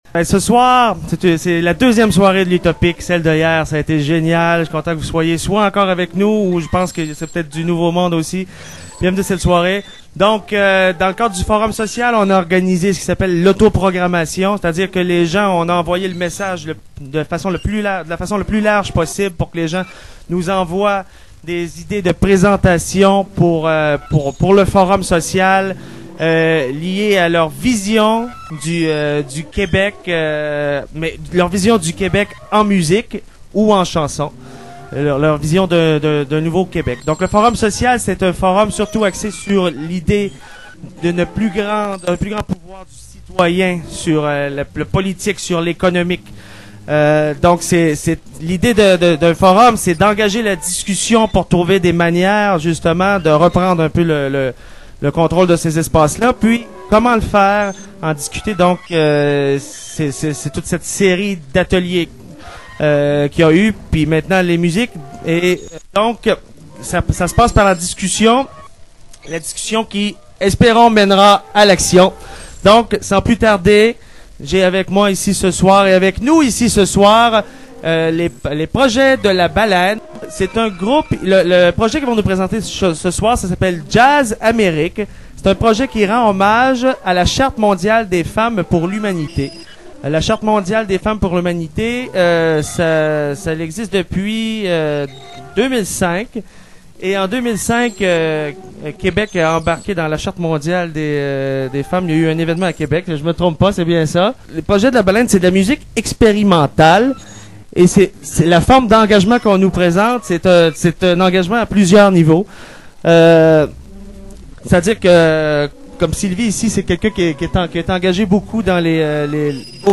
à la présentation.
7 - Présentation Forum social québécois (2:12)